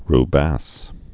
(r-băs, rbăs)